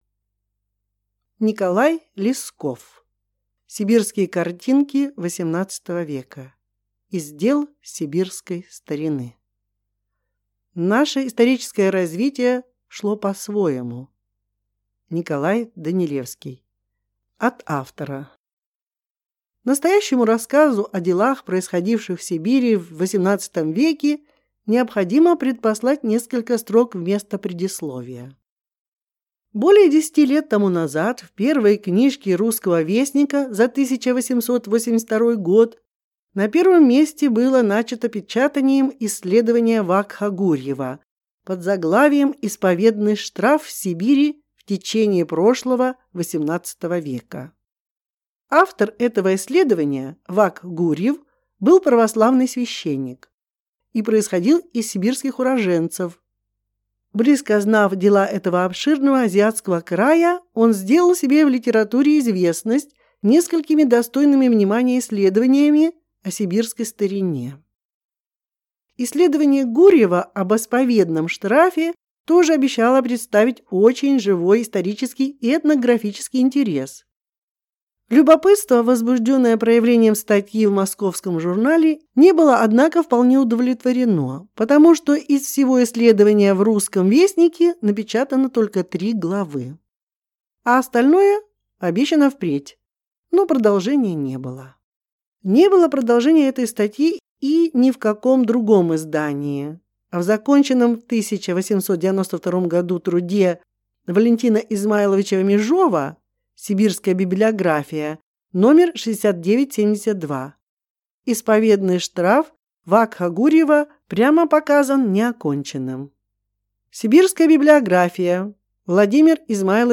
Аудиокнига Сибирские картинки XVIII века | Библиотека аудиокниг